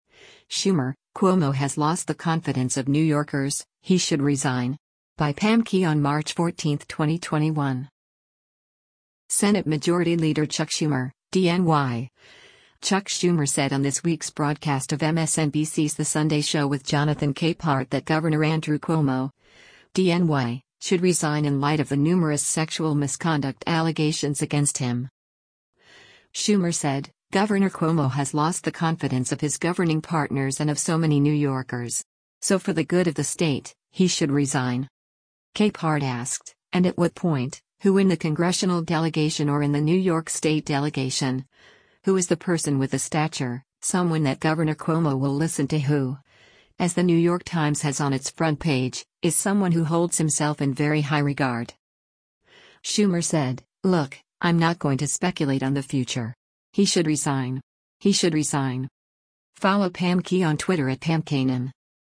Senate Majority Leader Chuck Schumer (D-NY) Chuck Schumer said on this week’s broadcast of MSNBC’s “The Sunday Show with Jonathan Capehart” that Gov. Andrew Cuomo (D-NY) “should resign” in light of the numerous sexual misconduct allegations against him.